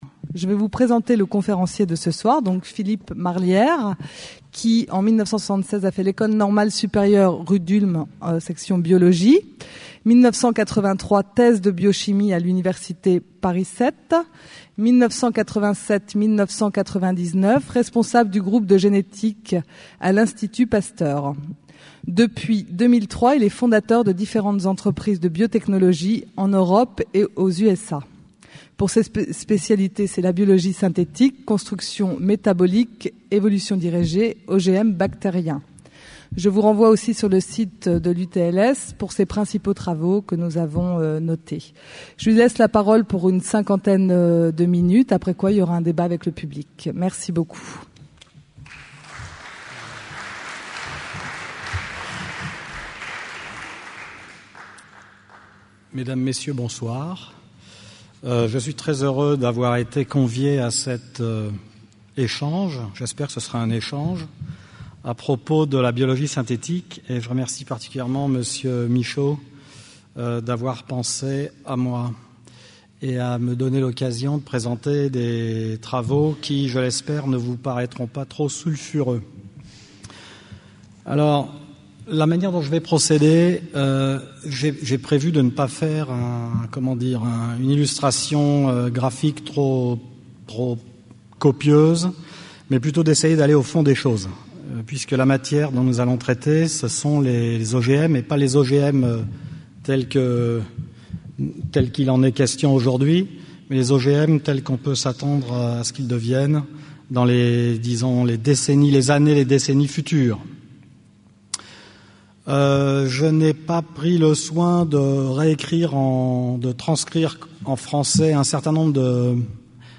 Une conférence du cycle : Qu'est ce que la vie ?